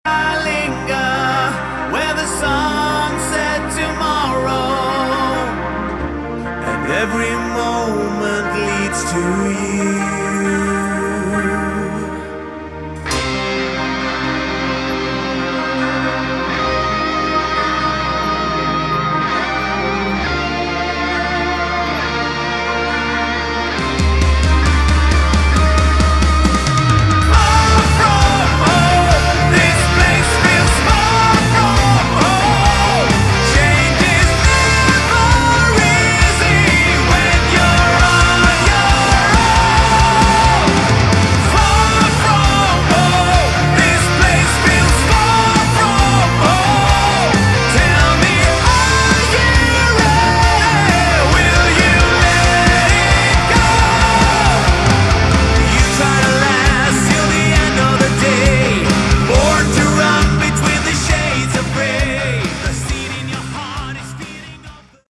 Category: Hard Rock
vocals
rhythm, lead, and acoustic guitars
bass
drums, percussion